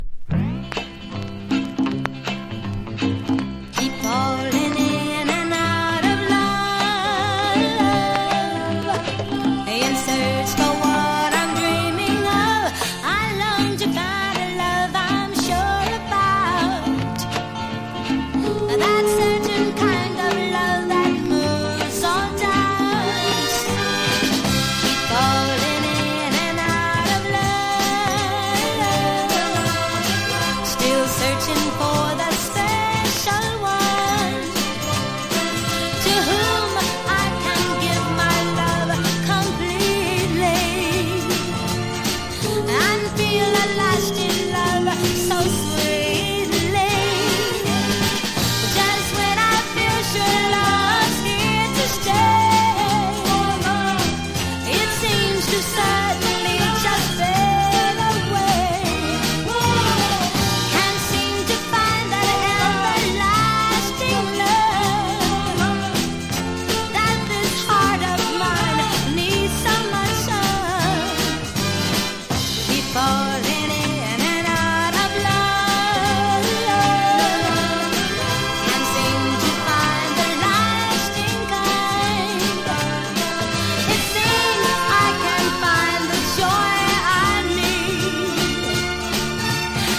曲調の穏やかなポップ・ソング